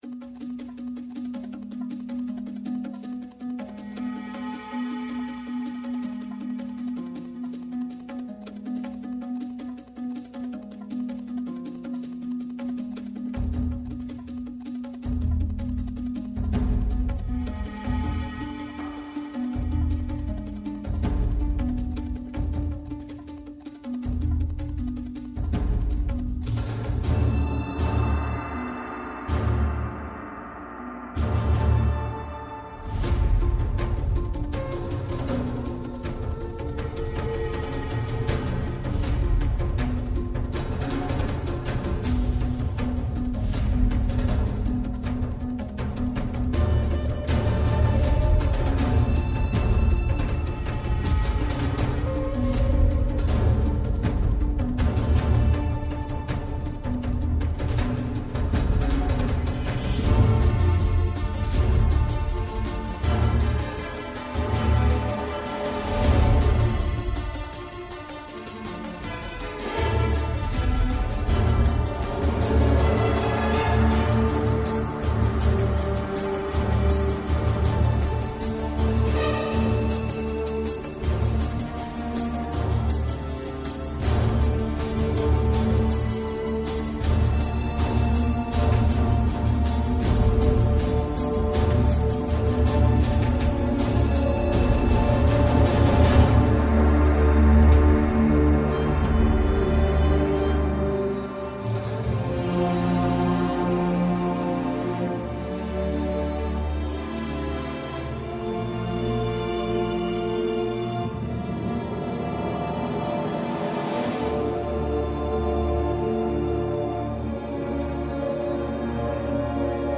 High impact dramatic film score music.
Tagged as: Electronica, Orchestral, Instrumental